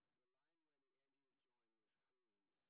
sp22_street_snr30.wav